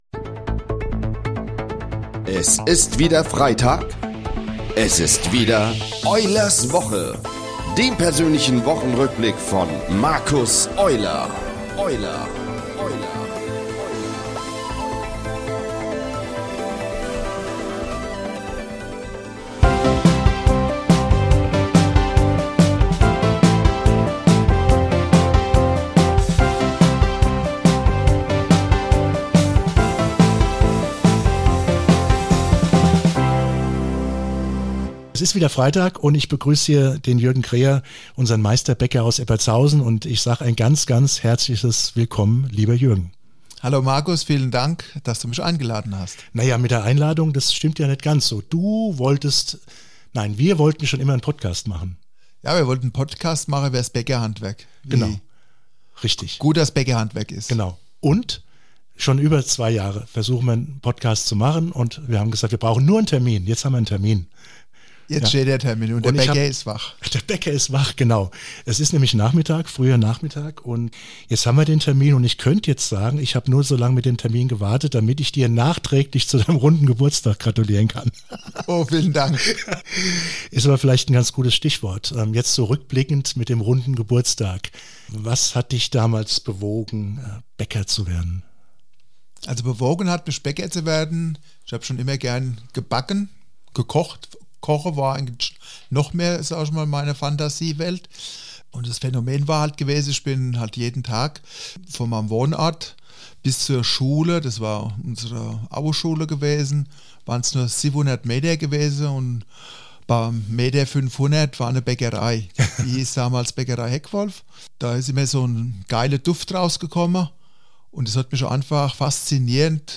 Und er definiert einen Ausblick auf die Zukunft der kleinen Handwerksbetriebe gegenüber der industriellen Fertigung. Ein ehrliches Interview voller Werte, Emotionen und dem Bekenntnis zum besten Fussballverein der Welt.